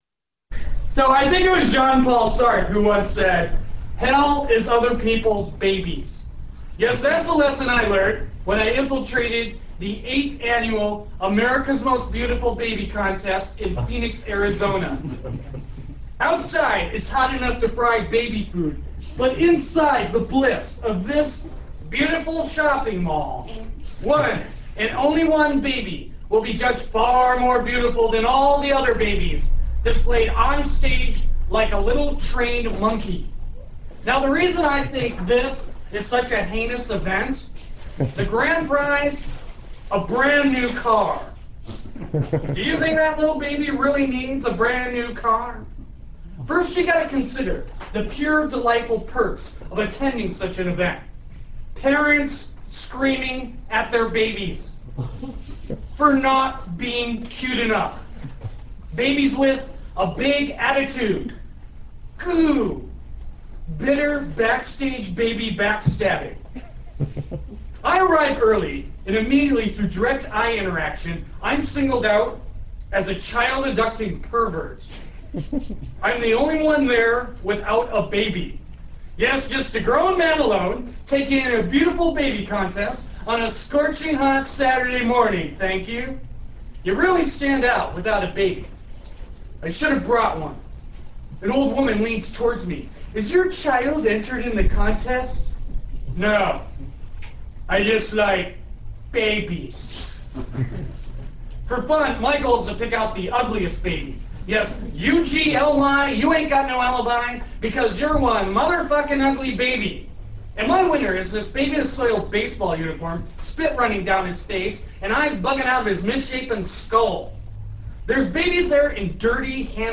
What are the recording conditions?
Live - Quimby's